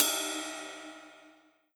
S_ride2_1.wav